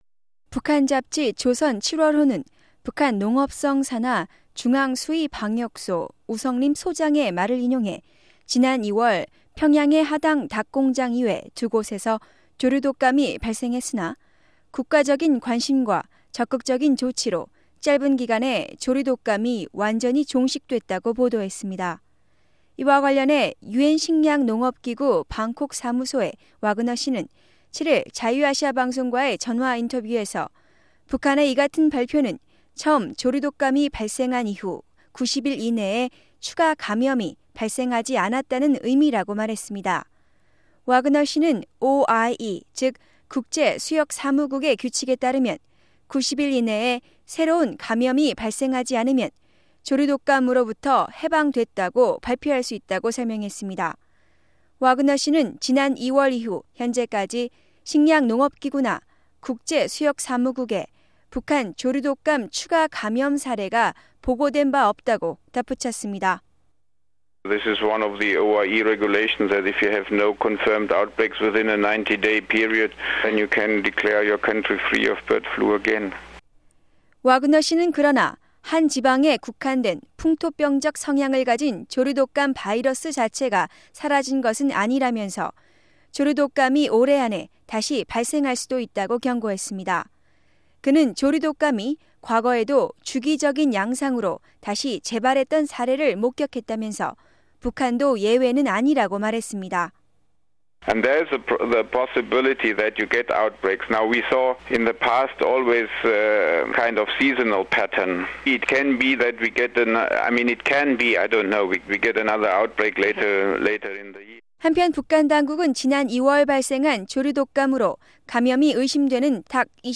Original reporting in Korean